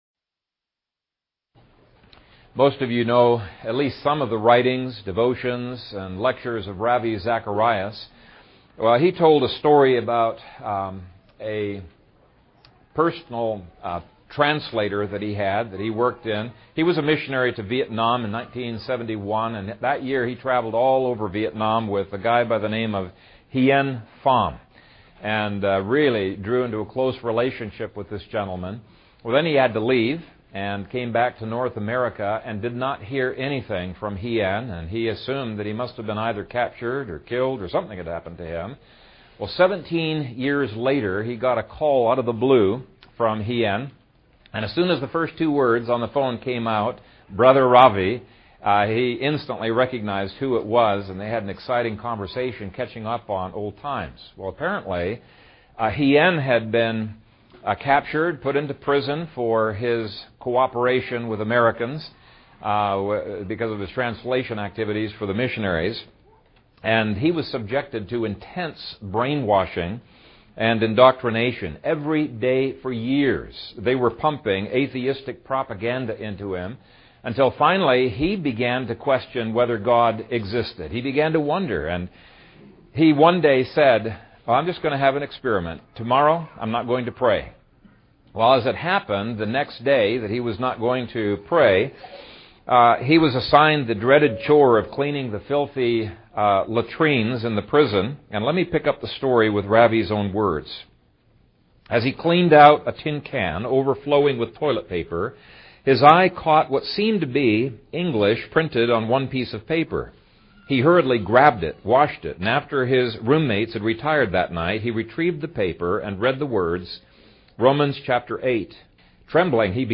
The Power of the Word | SermonAudio Broadcaster is Live View the Live Stream Share this sermon Disabled by adblocker Copy URL Copied!